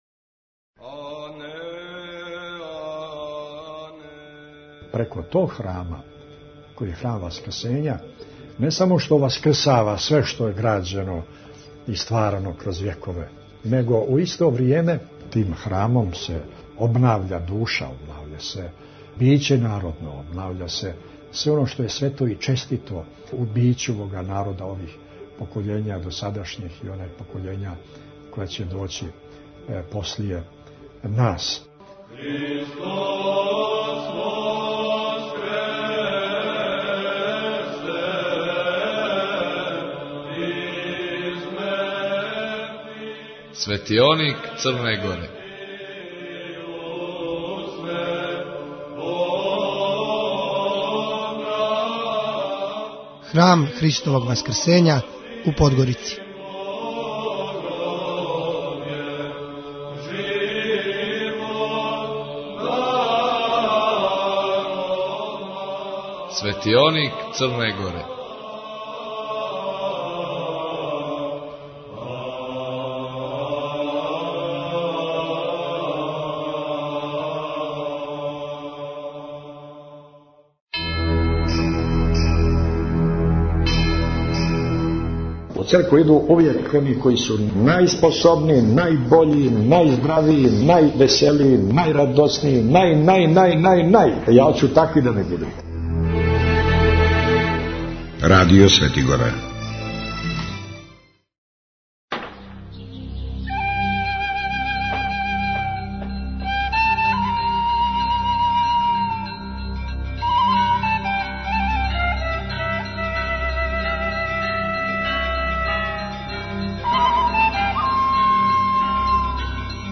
Светионик Црне Горе-Храм Христовог Васкрсења XI Tagged: Светионик Црне Горе-Храм Христовог Васкрсења у Подгорици 38:06 минута (6.54 МБ) У данашњем издању емисије слушаћете: - Фрагменте са Прес-а који је одржан на аеродрому "Никола Тесла" приликом дочека Патријараха у Београду. - Извјештај са докслогије која је у Саборној цркви у Београду одржана у част високих гостију.